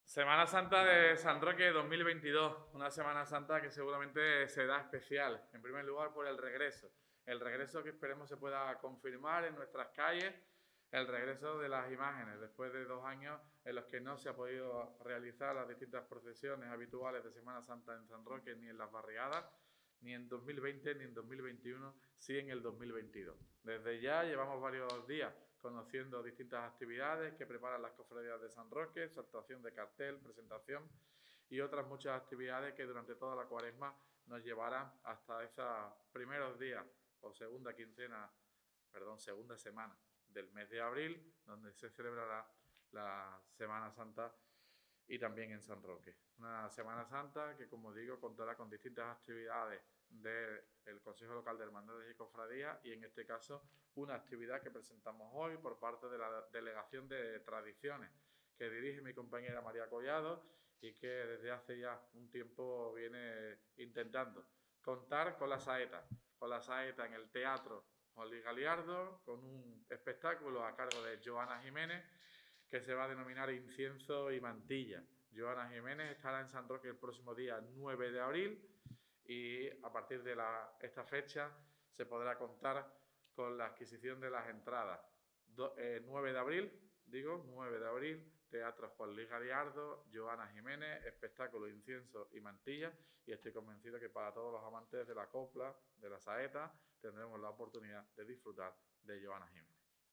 TOTAL_ALCALDE_CONCIETRO_JOANA.mp3